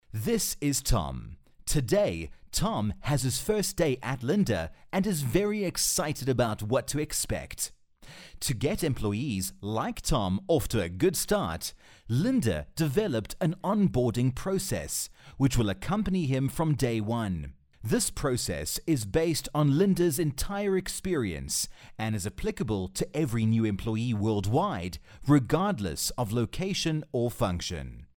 South African English Speaker with Neutral Accent.
Sprechprobe: eLearning (Muttersprache):